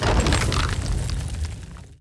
Free Fantasy SFX Pack
Rock Wall 2.ogg